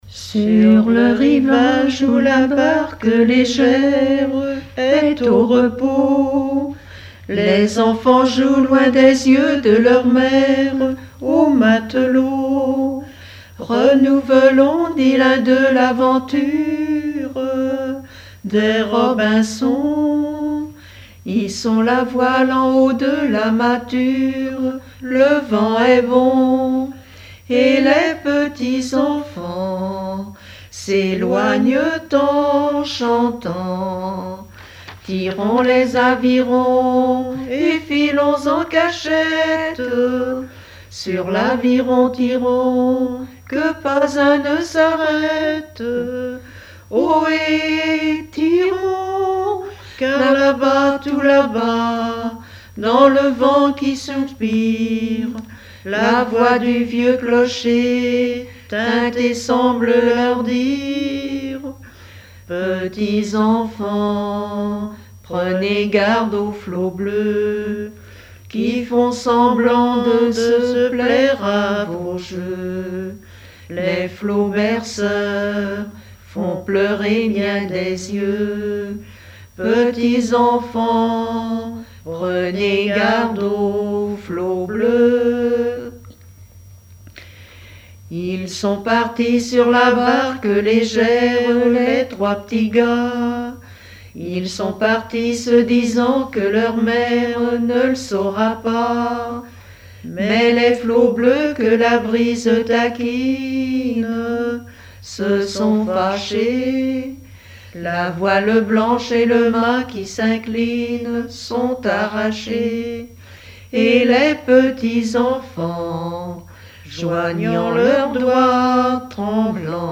Mémoires et Patrimoines vivants - RaddO est une base de données d'archives iconographiques et sonores.
Pièce musicale inédite